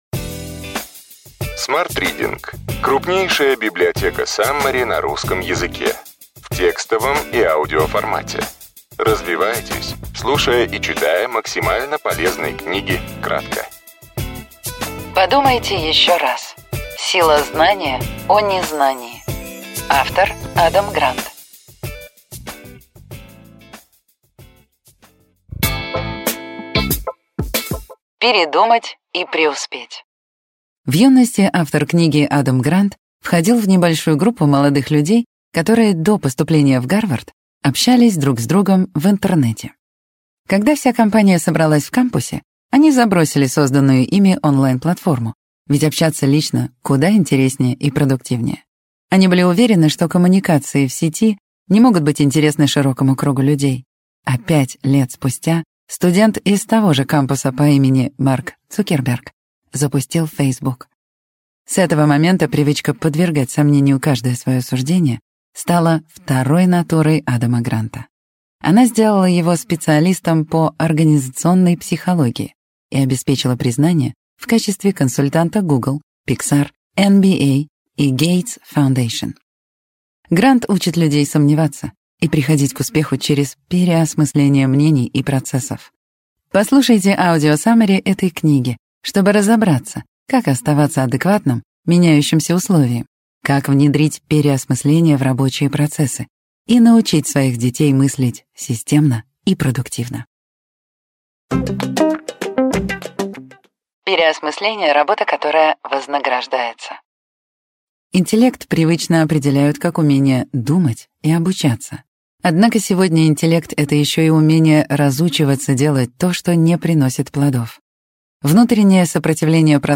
Аудиокнига Ключевые идеи книги: Подумайте еще раз. Сила знания о незнании.